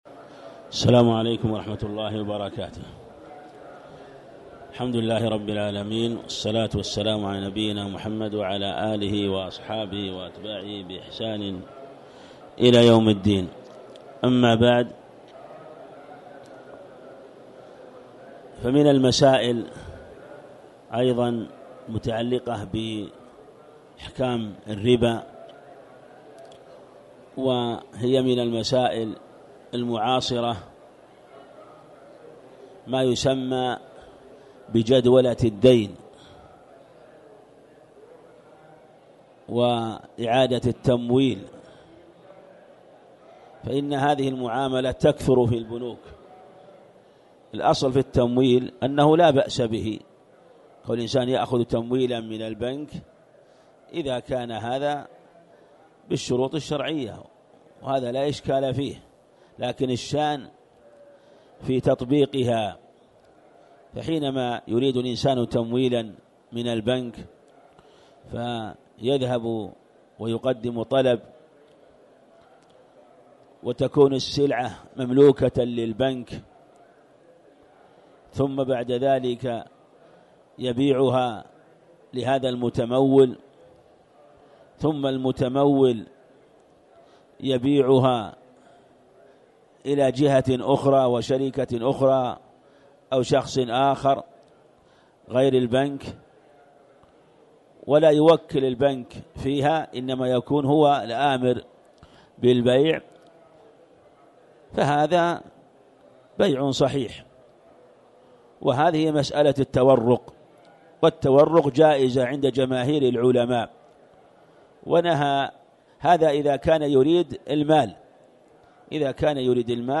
تاريخ النشر ٧ رمضان ١٤٣٨ هـ المكان: المسجد الحرام الشيخ